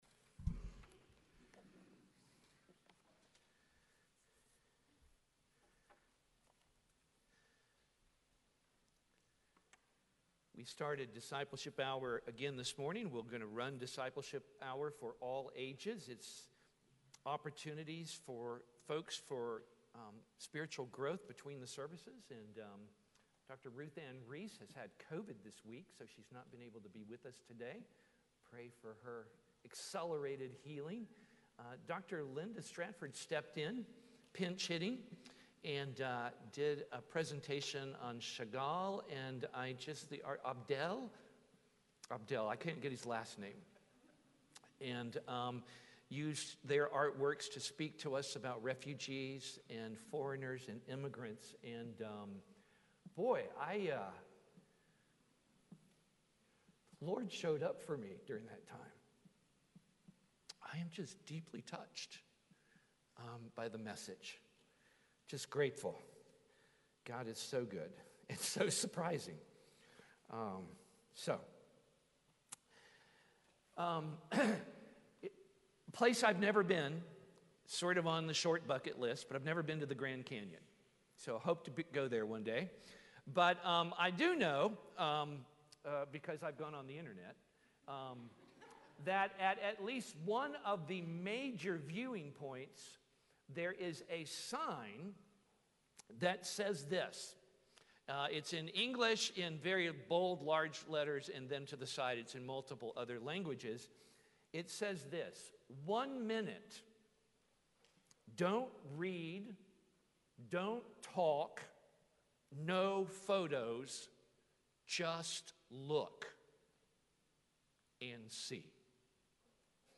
Posted on Mar 1, 2023 in Sermons, Worship |